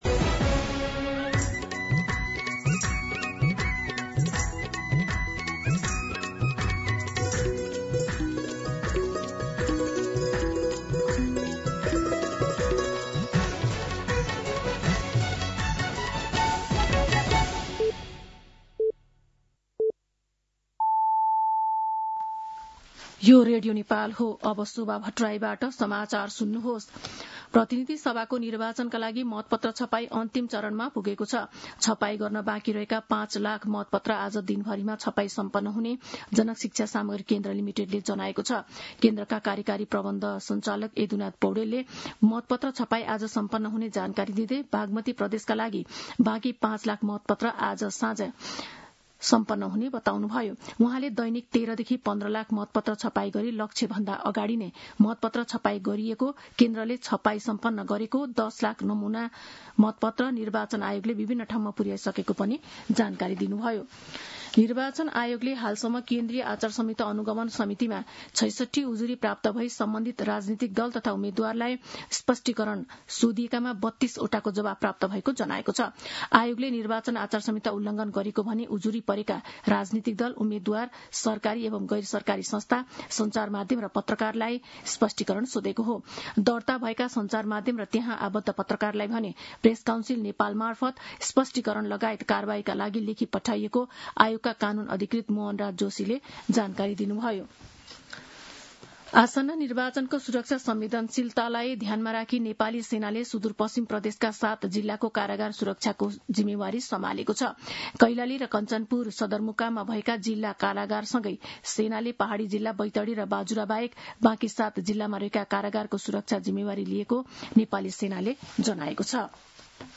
दिउँसो ४ बजेको नेपाली समाचार : ४ फागुन , २०८२
4-pm-News-11-4.mp3